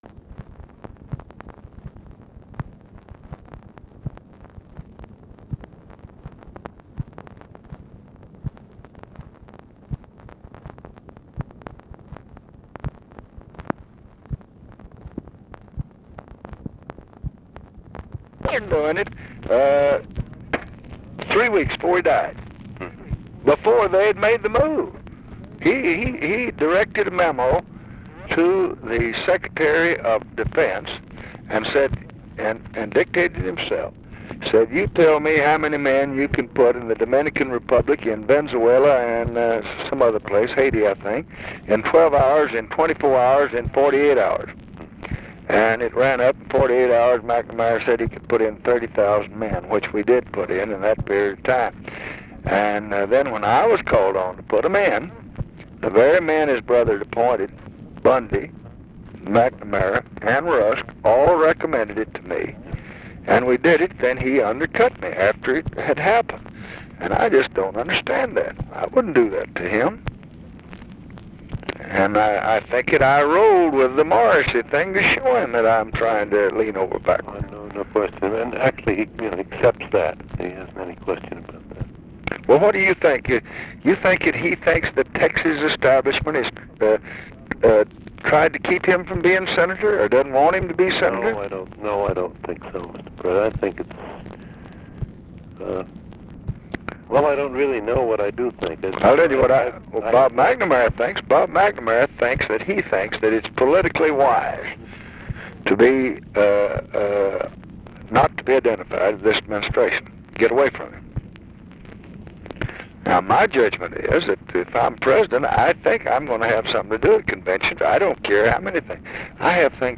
Conversation with NICHOLAS KATZENBACH, March 18, 1966
Secret White House Tapes